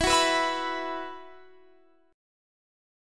Esus2 Chord (page 3)
Listen to Esus2 strummed